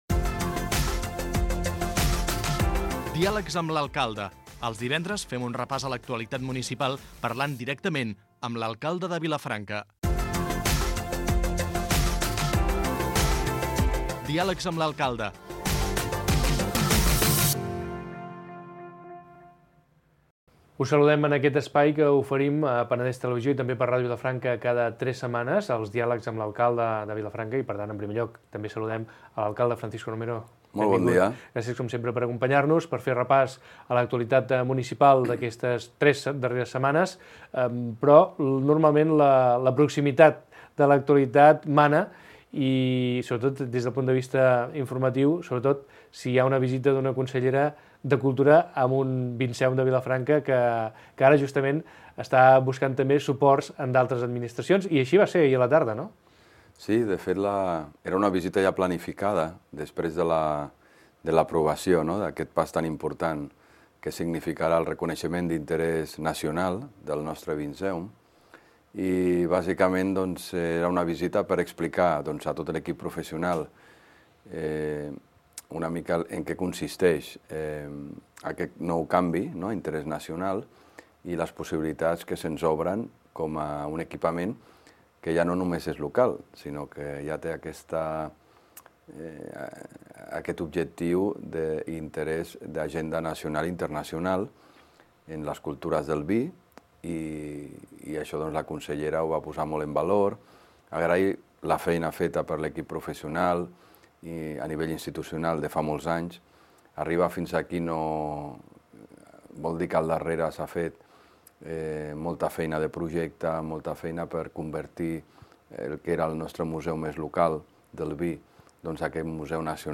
Entrevista amb l'alcalde Francisco Romero